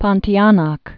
(pŏntēnäk)